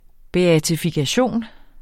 Udtale [ beatifikaˈɕoˀn ]